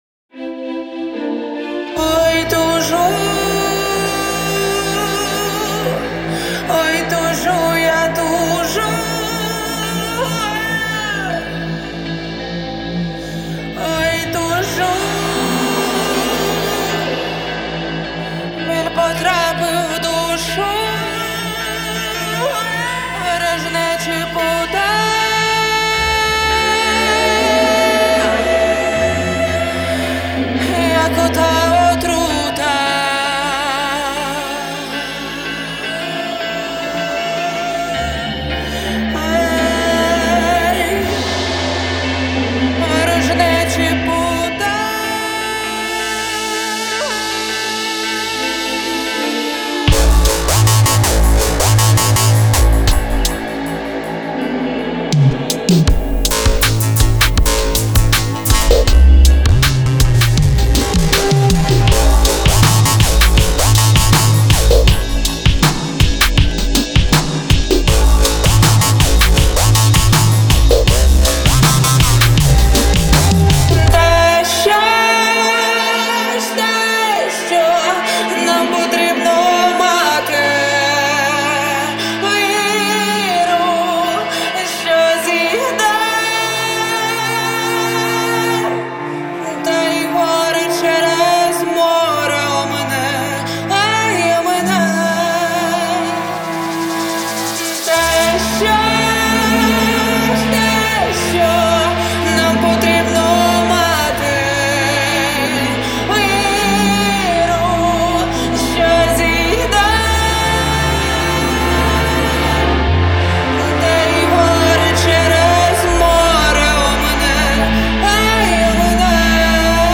• Жанр: Electronic, Indie